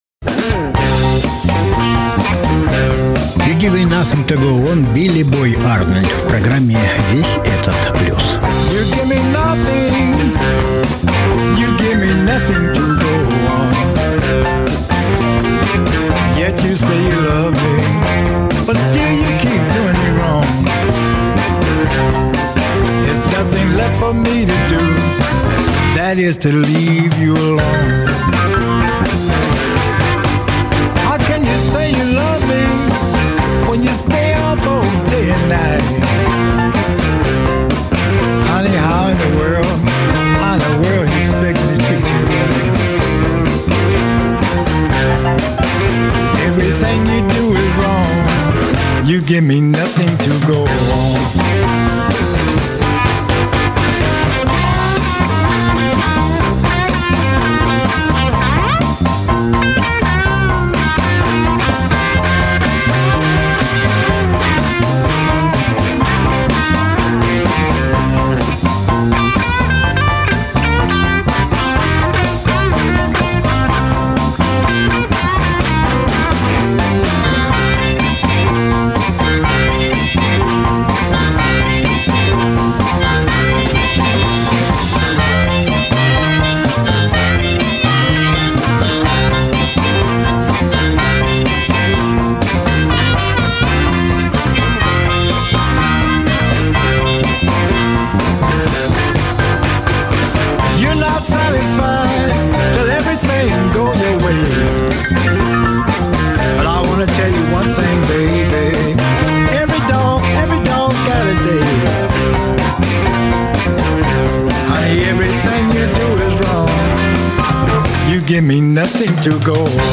СОДЕРЖАНИЕ 10.11.2014 Сегодня обозреваем осенние новинки блюзовой фонотеки.